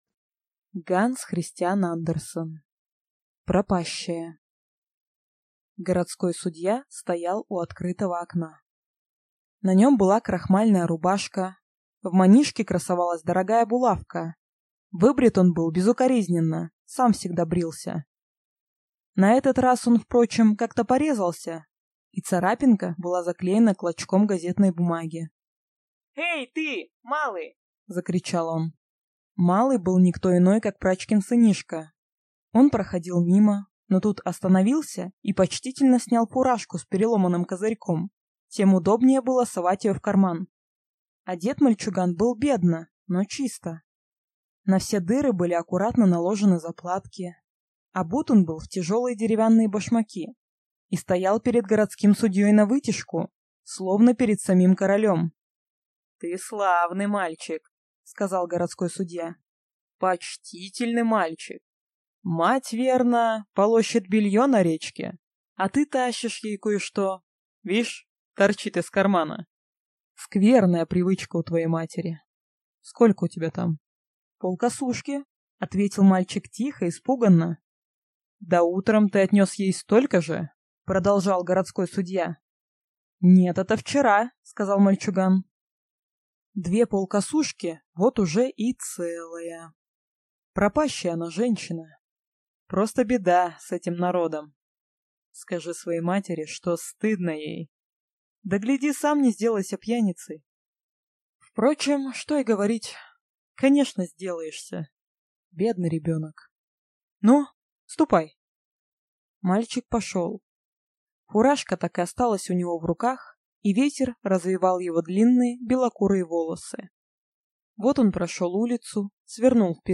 Аудиокнига Пропащая | Библиотека аудиокниг
Читает аудиокнигу